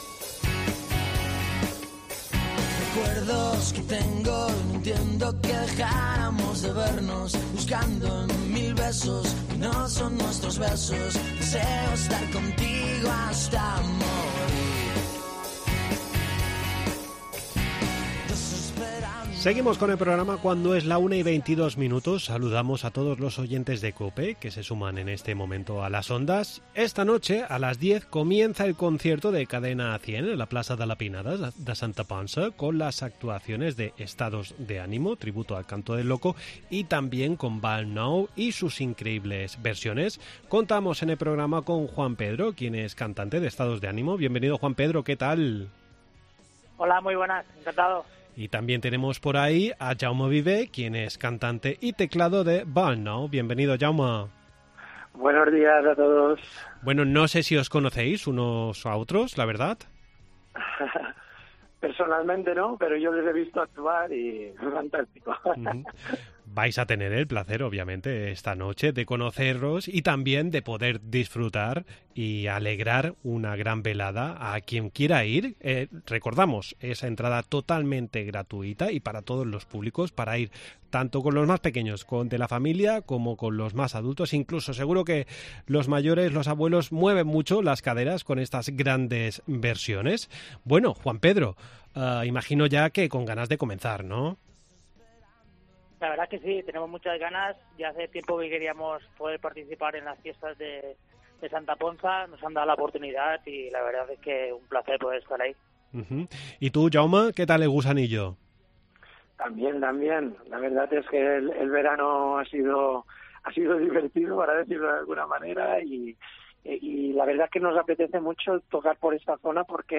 AUDIO: Charlamos con los cantantes del concierto de Cadena 100, cuyo inicio es a las 22h.